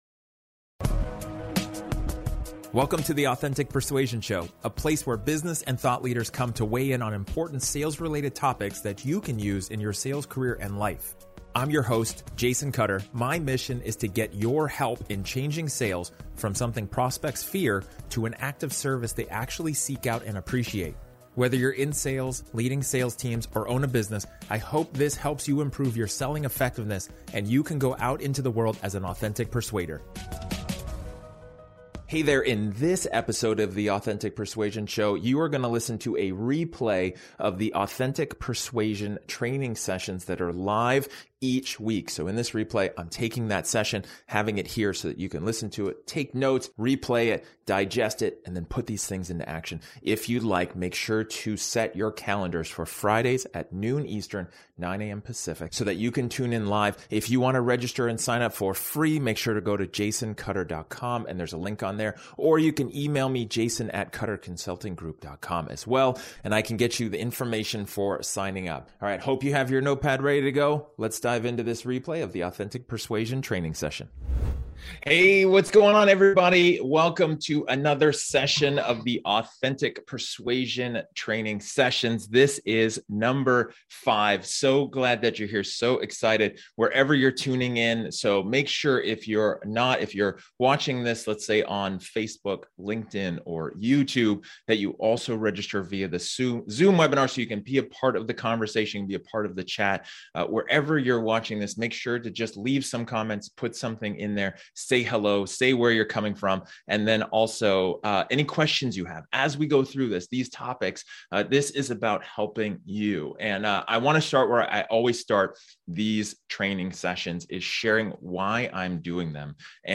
In this episode of my live training session, I discuss all you need to know about leadership and its elements.